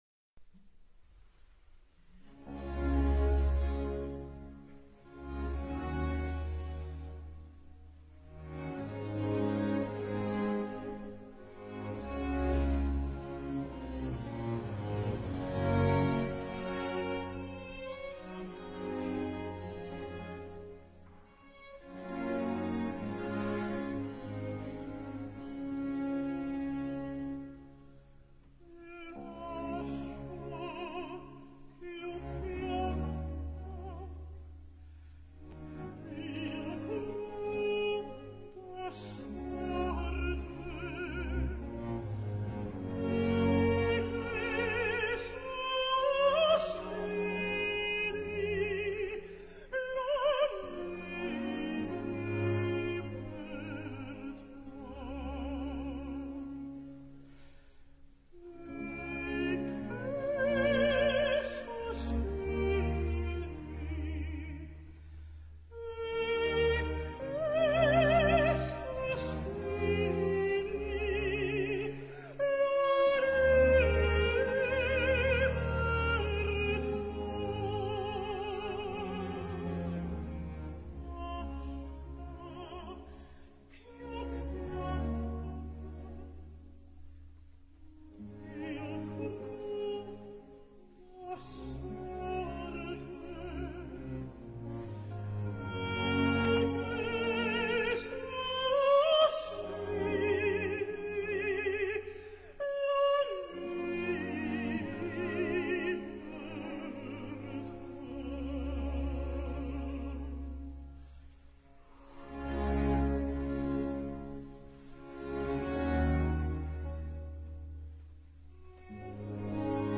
CONTRALTO